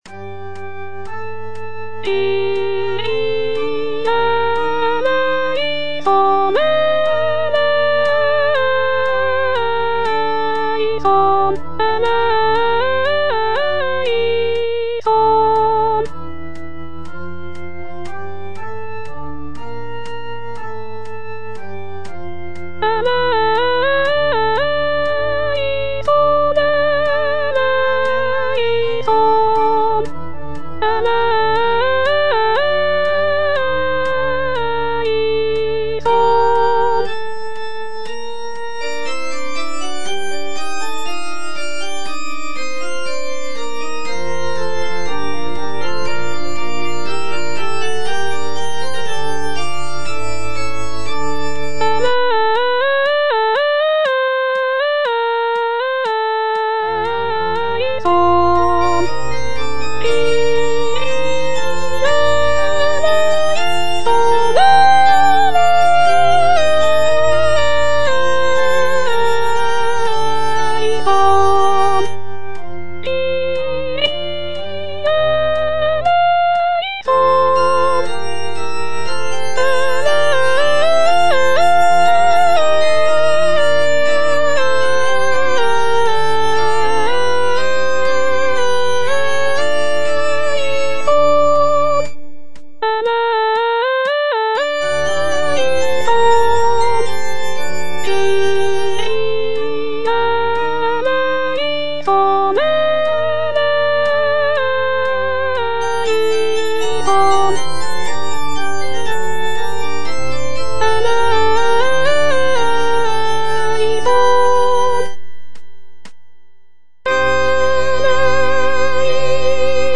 A. LOTTI - MISSA SAPIENTIAE Kyrie (II) - Soprano (Voice with metronome) Ads stop: auto-stop
This sacred composition is a Mass setting, which includes sections such as Kyrie, Gloria, Credo, Sanctus, and Agnus Dei. Lotti's "Missa Sapientiae" showcases his mastery of counterpoint, with intricate polyphonic textures and rich harmonies.